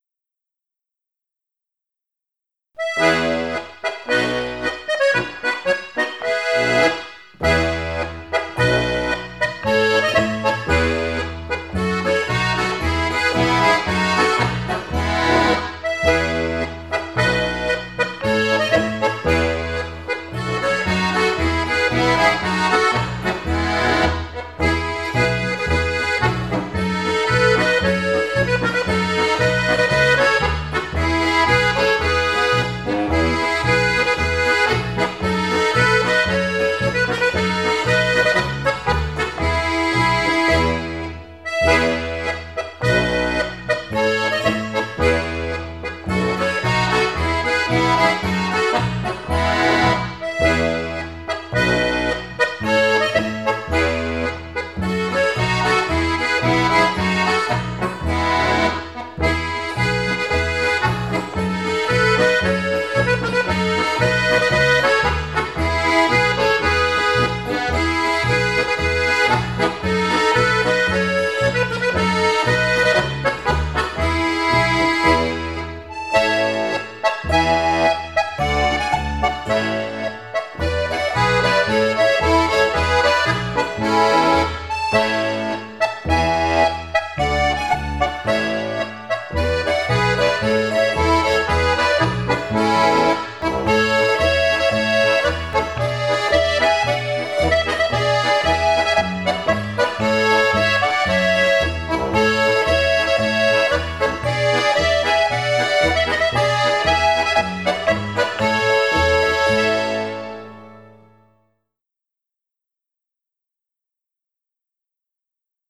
Krebs – Polka – ARGE Volkstanz Kaernten